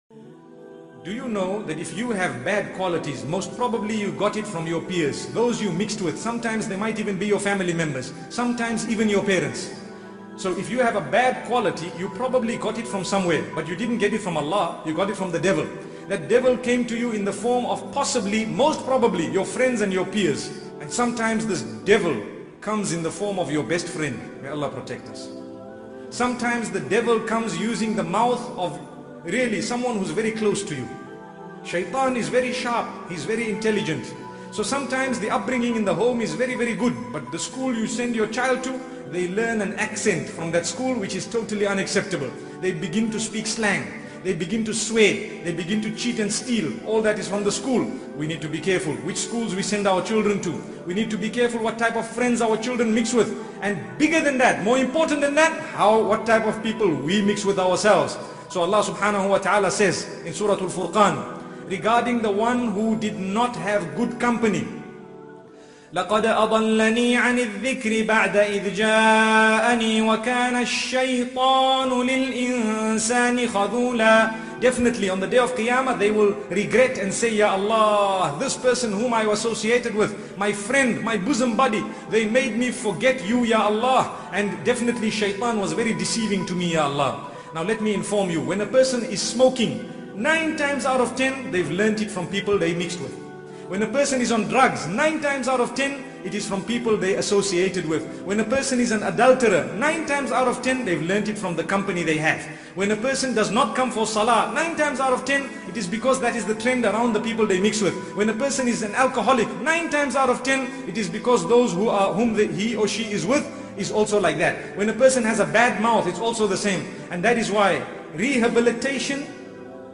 A powerful reminder from Mufti Ismail Menk.